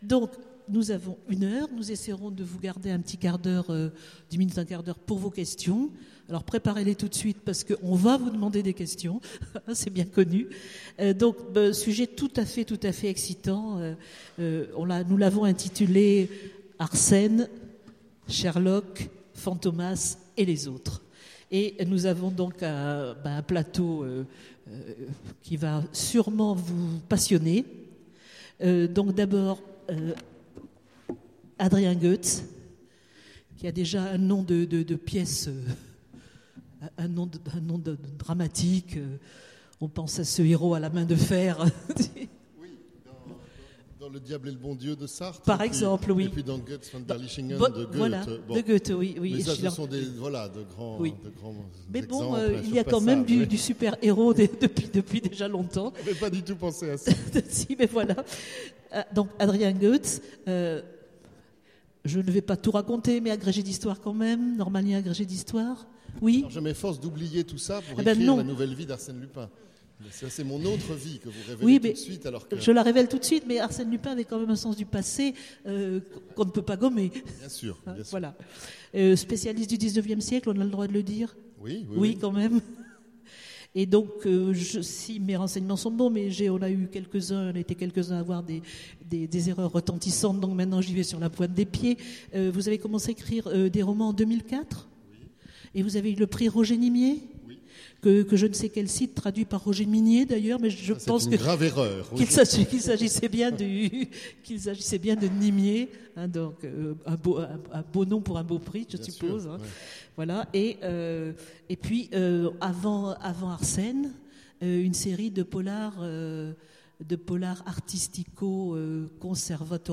Étonnants Voyageurs 2015 : Conférence Arsène, Sherlock, Fantômas et les autres